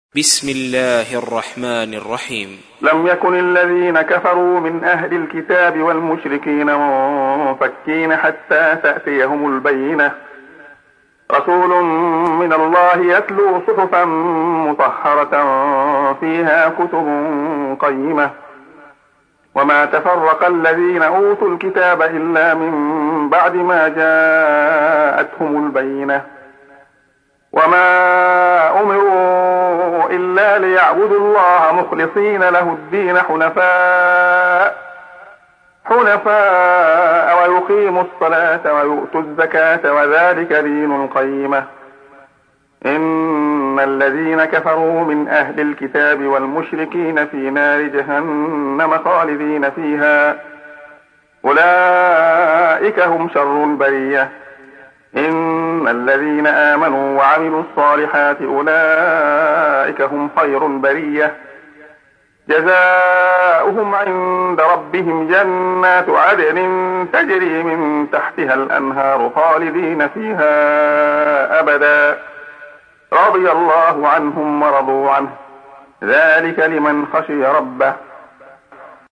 تحميل : 98. سورة البينة / القارئ عبد الله خياط / القرآن الكريم / موقع يا حسين